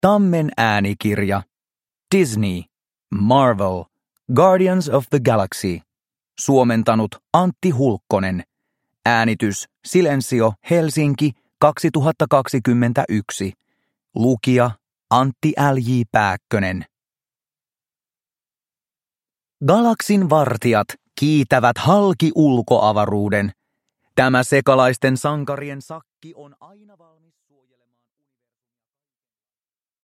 Marvel. Guardians of the Galaxy. Galaksin vartijat. – Ljudbok – Laddas ner